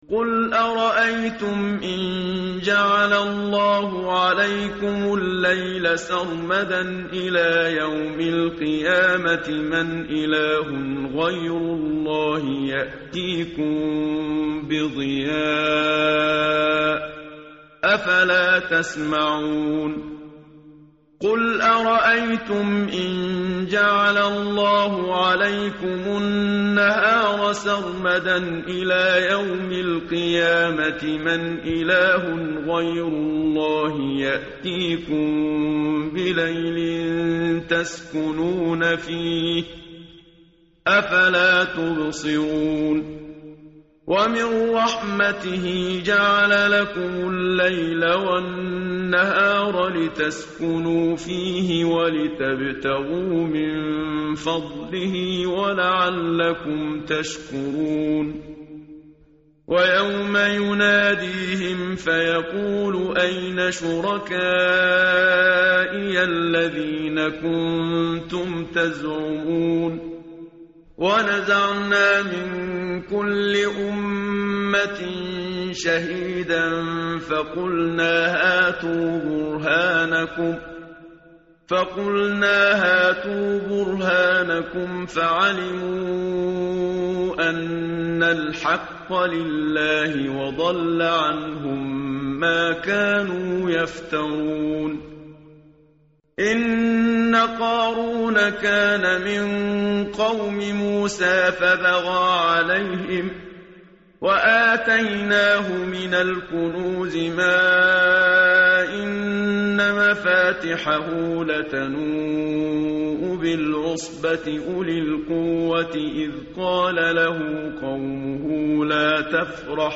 متن قرآن همراه باتلاوت قرآن و ترجمه
tartil_menshavi_page_394.mp3